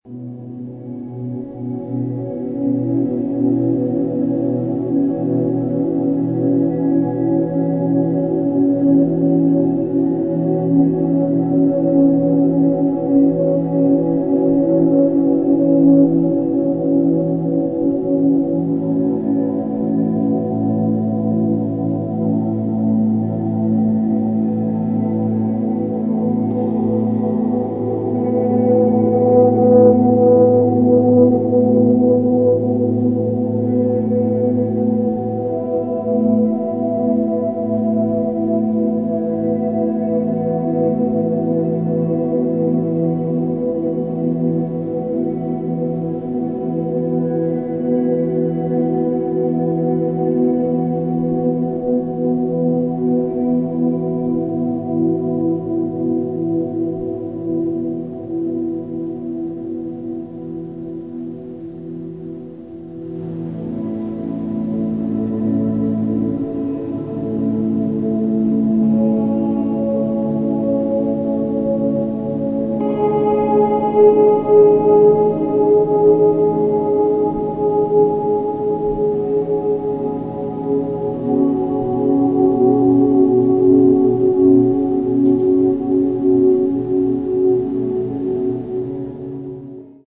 沈黙の中に響く微かなノイズ。
ギターの透明な響きは美しい終わりの光景へと溶けていきます。